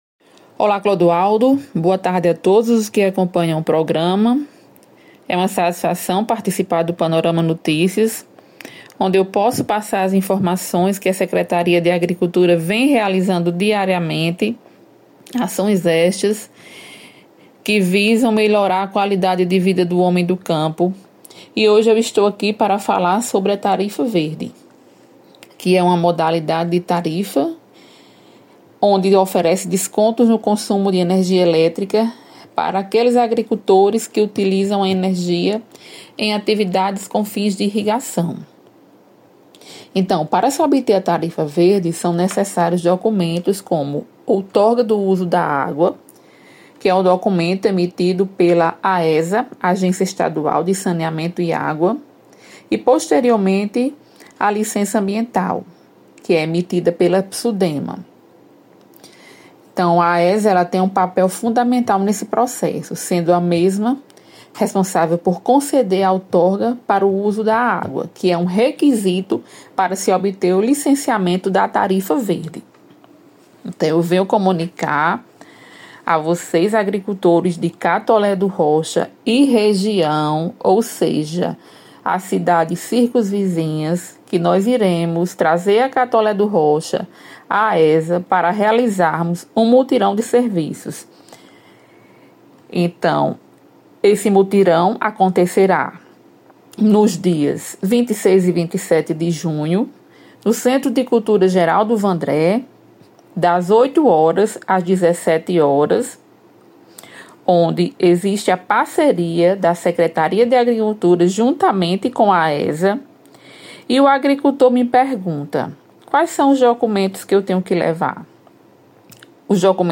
A secretária municipal de agricultura, Claudinete Costa, participa do panorama notícias e traz mais informações.